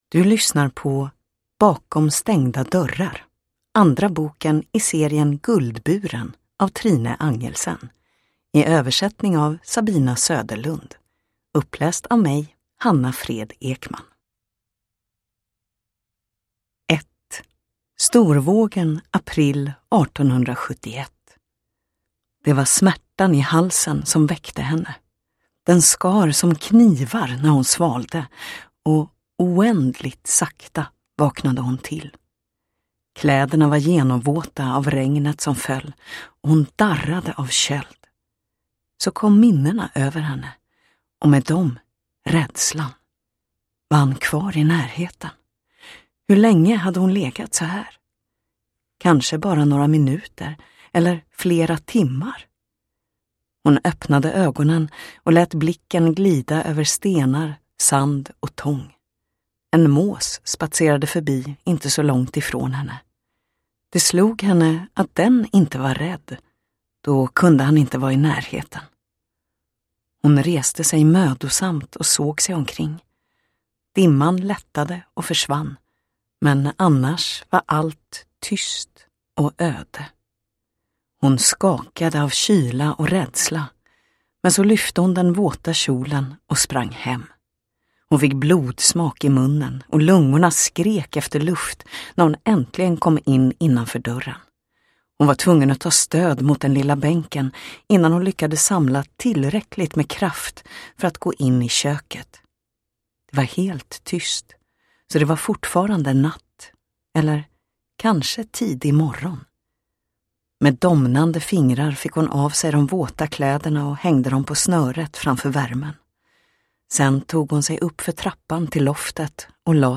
Bakom stängda dörrar – Ljudbok